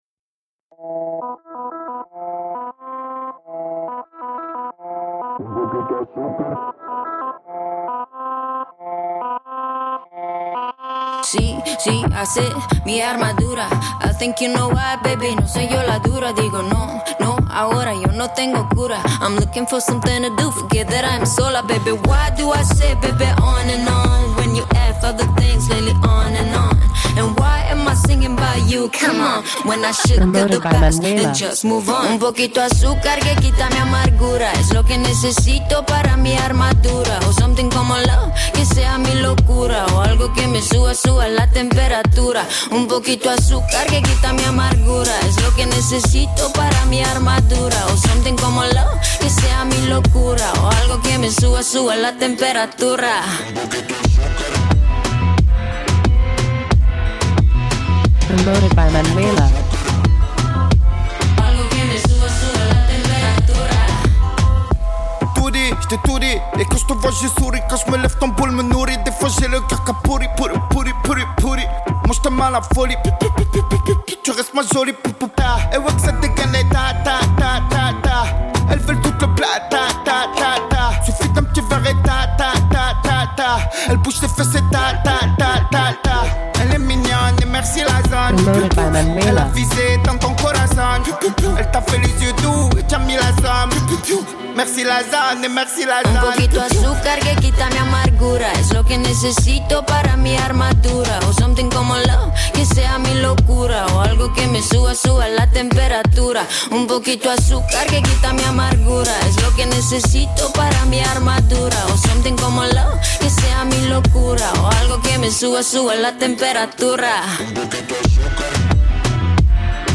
Radio Edit
Doux comme du sucre mais enflammé et irrésistible